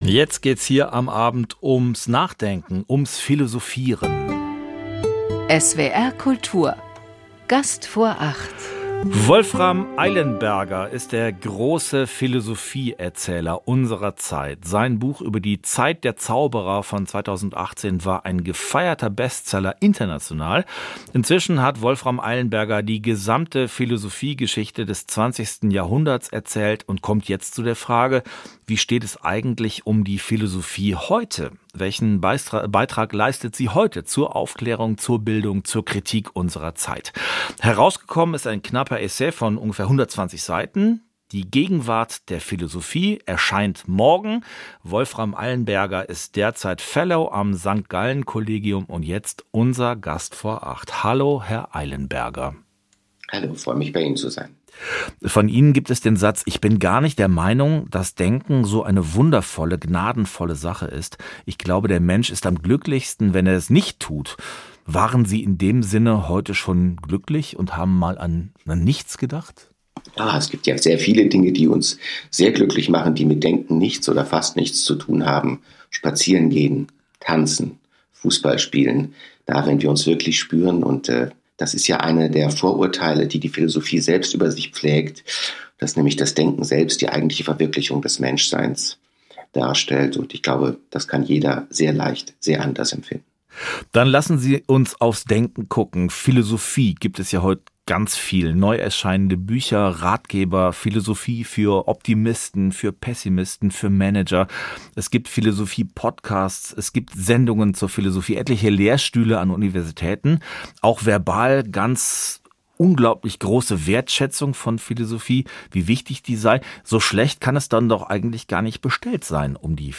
Wolfram Eilenberger spricht über die Gegenwart der Philosophie
Gespräch mit Wolfram Eilenberger über die Gegenwart der Philosophie